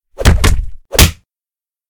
环境音
0006_被踩力.ogg